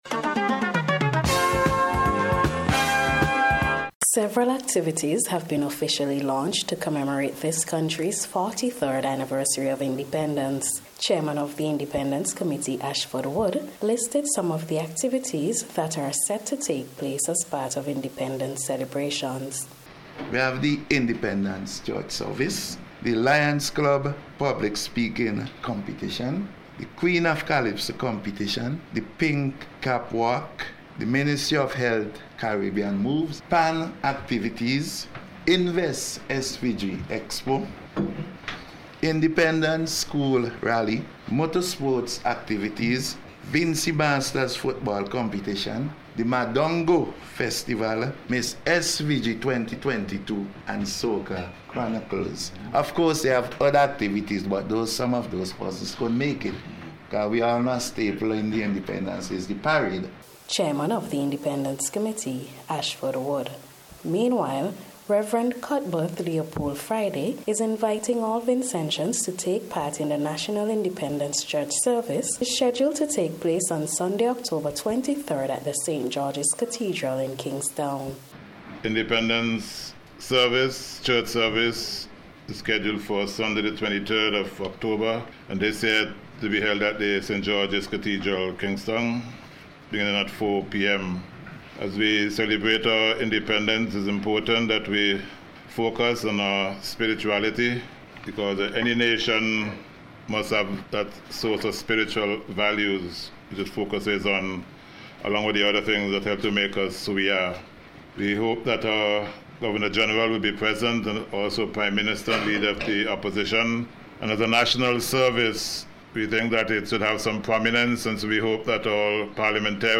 special report